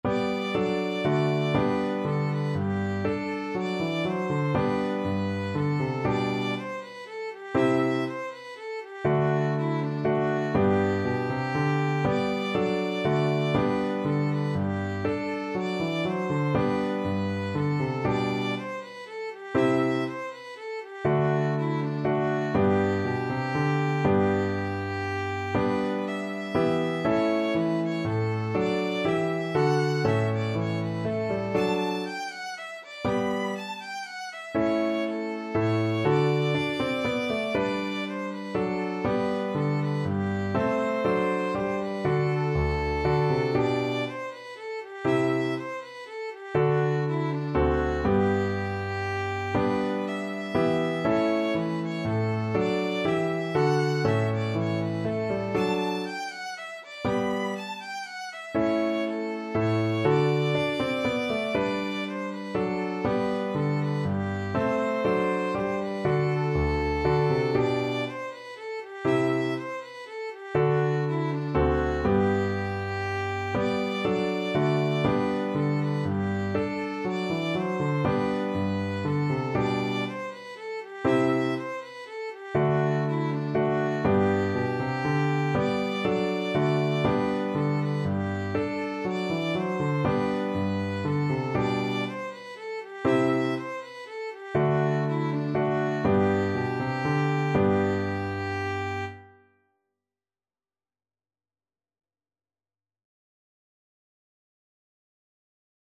Classical Bach, Johann Sebastian Minuet III from Suite in G minor, BWV 822 Violin version
Violin
3/4 (View more 3/4 Music)
~ = 100 Allegretto =120
D5-B6
G major (Sounding Pitch) (View more G major Music for Violin )
Classical (View more Classical Violin Music)
bach_minuet3_bwv822_VLN.mp3